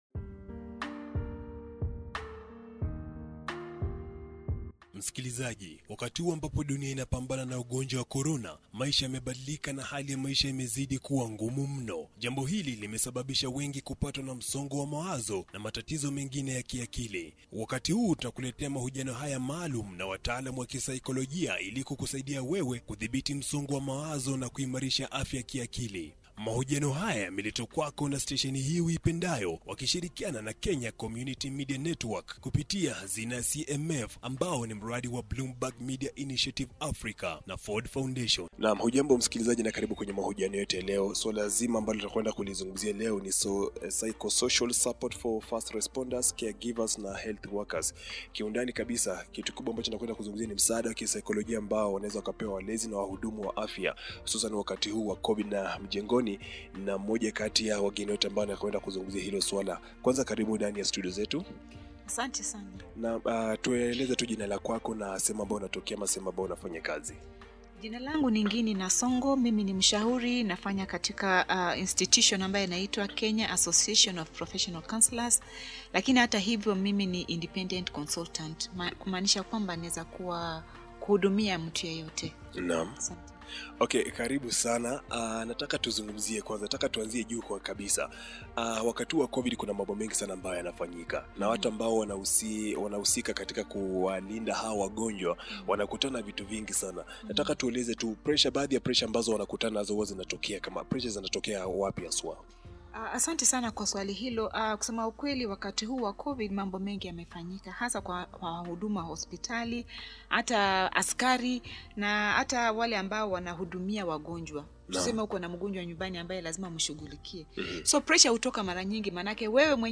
Listento this Swahili interview